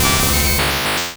Cri de Raichu dans Pokémon Rouge et Bleu.